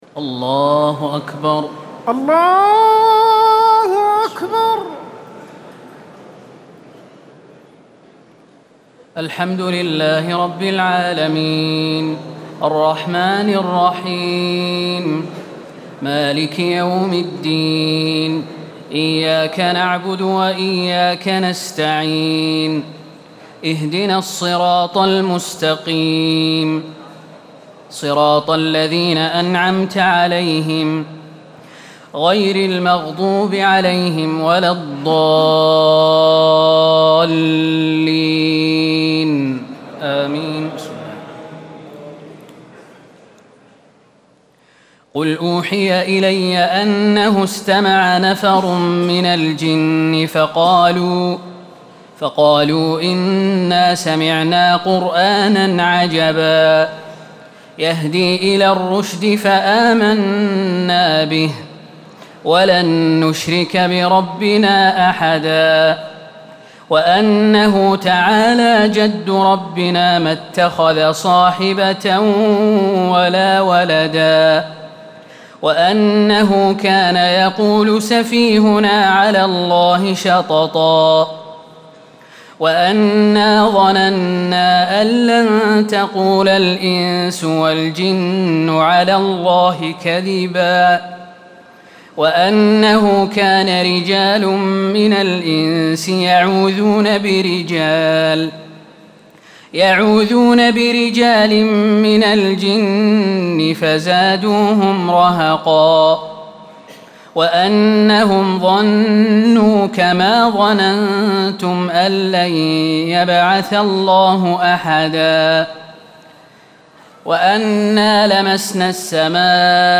تراويح ليلة 28 رمضان 1437هـ من سورة الجن الى المرسلات Taraweeh 28 st night Ramadan 1437H from Surah Al-Jinn to Al-Mursalaat > تراويح الحرم النبوي عام 1437 🕌 > التراويح - تلاوات الحرمين